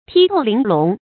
剔透玲珑 tī tòu líng lóng 成语解释 ①形容灵巧可爱。